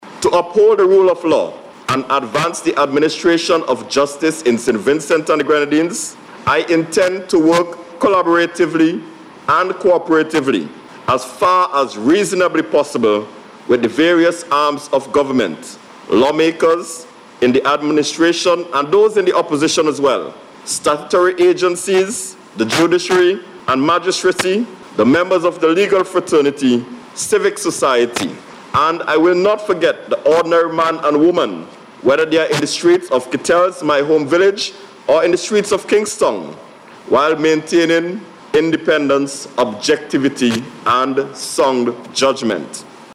Attorney at Law, Grenville Williams was officially sworn in as the new Attorney General of St. Vincent and the Grenadines, during a ceremony at Government House this morning
WILLIAMS-SWEARING-IN-1.mp3